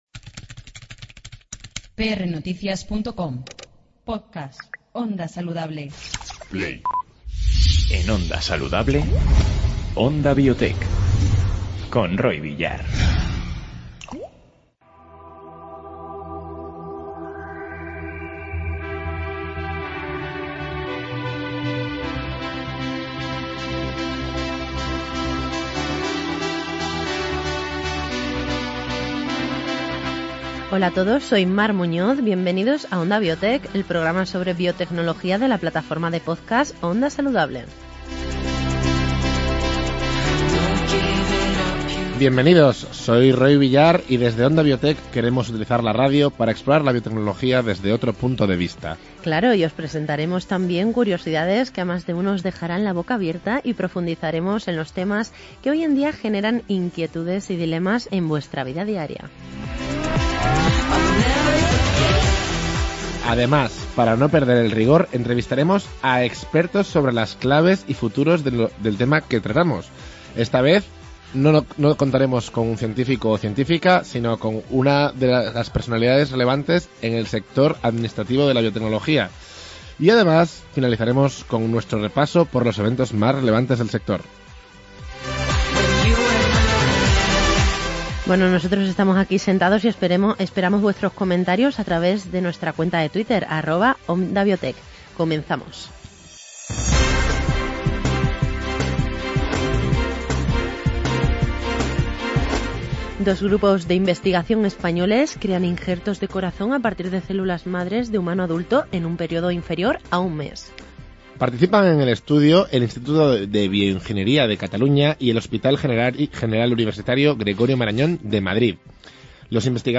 En este programa entrevistamos a expertos sobre las claves y los futuros de las biorregiones. Además repasamos cómo dos grupos de investigación españoles crean injertos de corazón a partir de células madre de humano adulto en un período inferior a un mes.